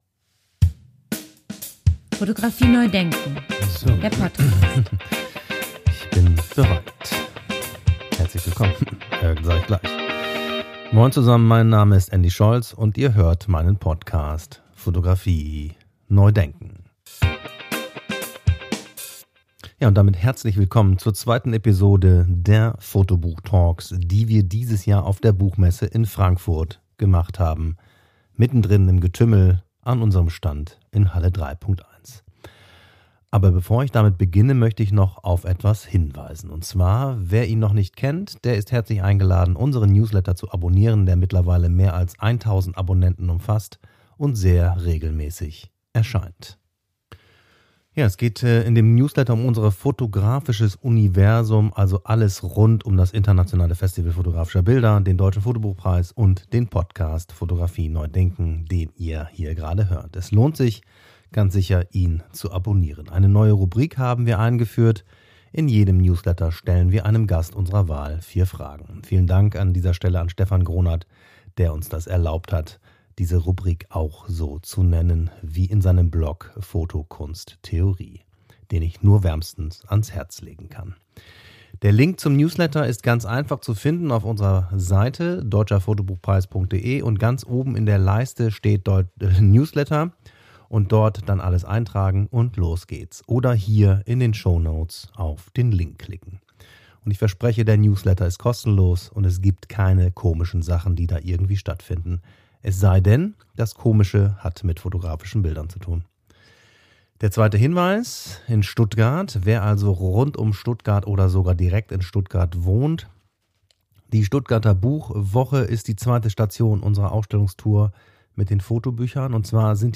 Fotobuch-Talk. Frankfurter Buchmesse 2024. 25:17